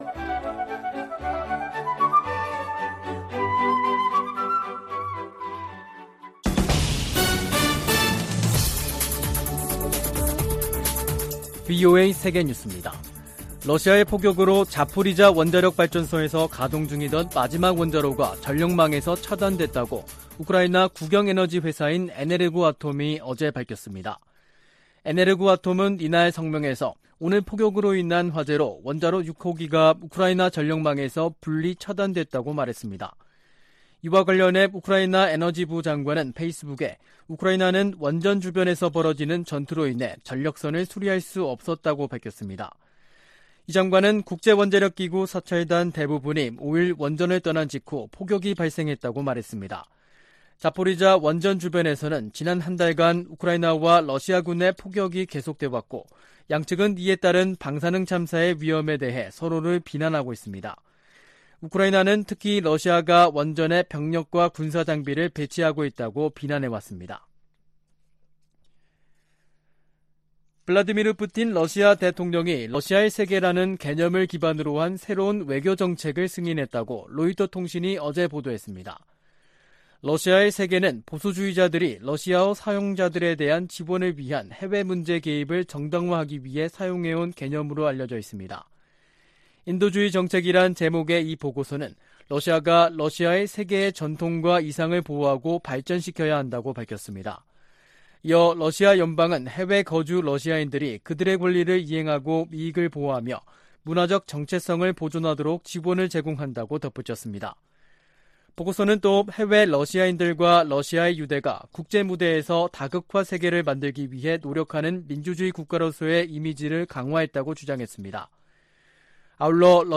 VOA 한국어 간판 뉴스 프로그램 '뉴스 투데이', 2022년 9월 6일 2부 방송입니다. 미국과 한국, 일본 외교수장들은 북한이 7차 핵실험을 감행할 경우 이전과는 다른 대응을 예고했습니다. 미한일 협력이 강조되는 가운데, 한일관계에는 여전히 온도차가 있다고 미국 전문가들이 지적했습니다. 봄 가뭄과 ‘코로나’ 이중고를 겪고 있는 북한 경제에 “개혁・개방이 살 길”이라고 미국 전문가들이 진단했습니다.